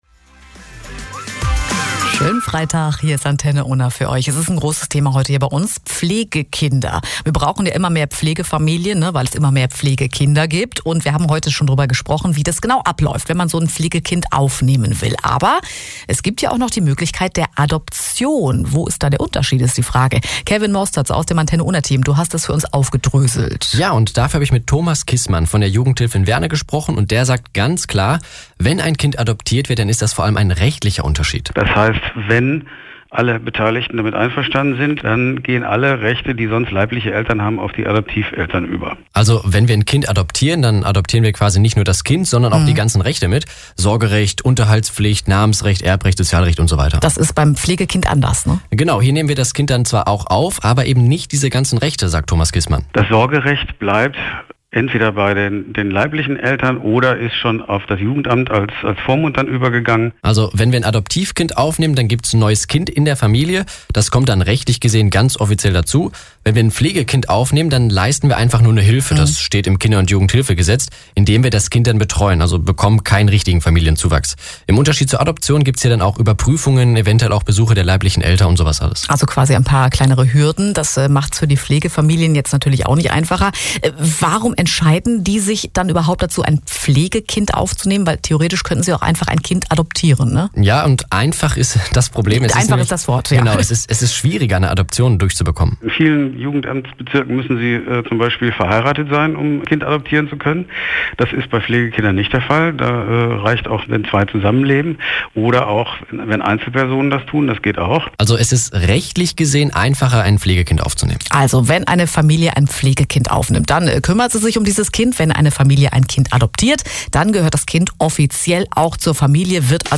Antenne_Unna_Mai2019_Mitschnitt_Jugendhilfe_WerneII.mp3